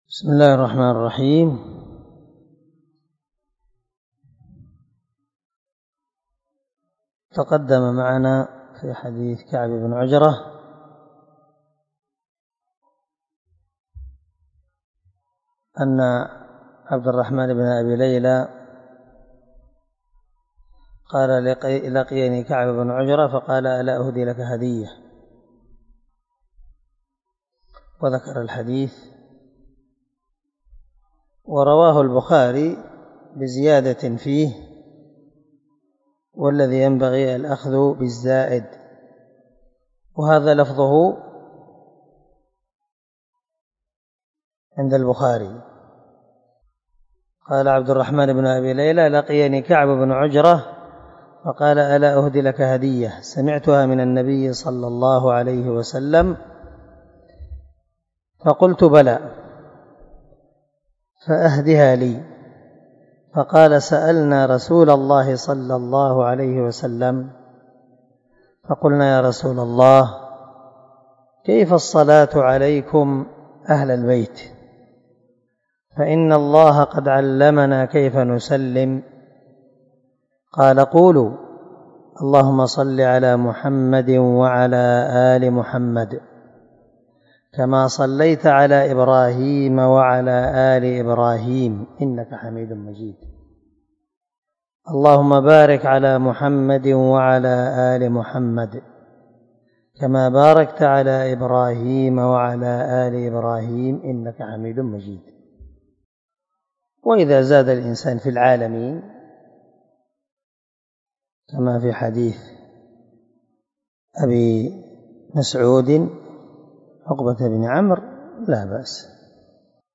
283الدرس 27 من شرح كتاب الصلاة حديث رقم ( 408 ) من صحيح مسلم